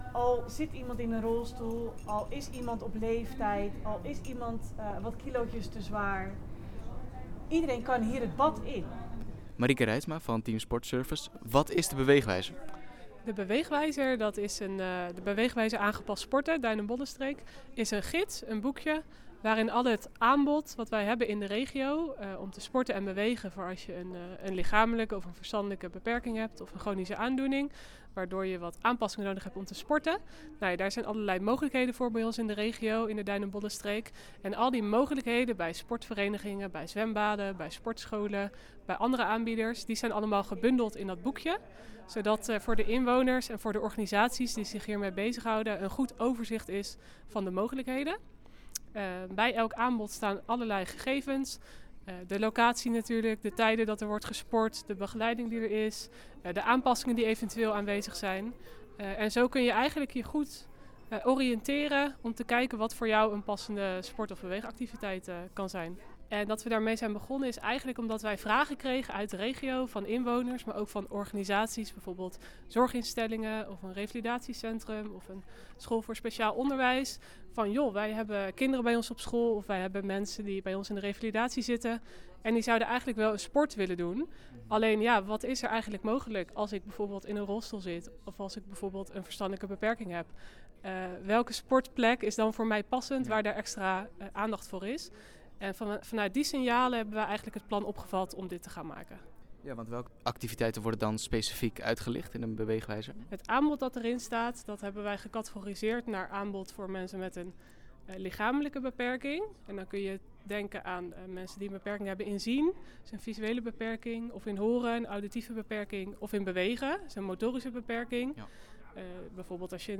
4-8-25-audio-reportage-beweegwijzer.mp3